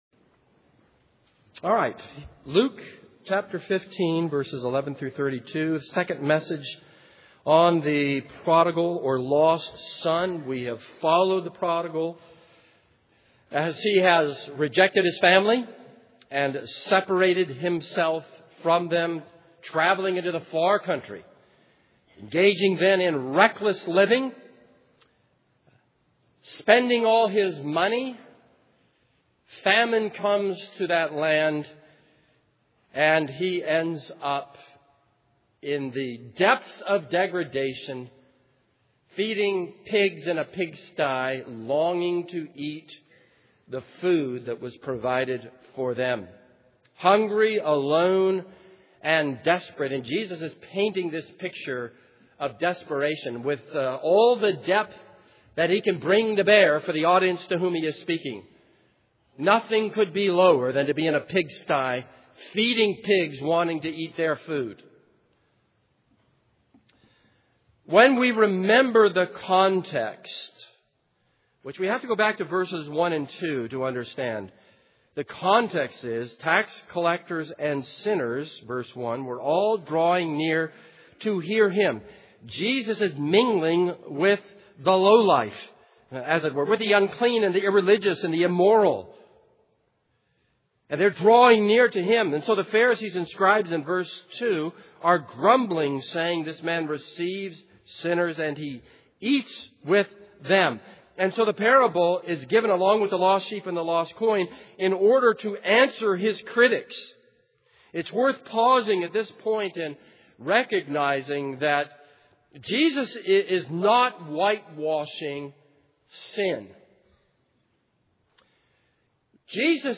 This is a sermon on Luke 15:11-32.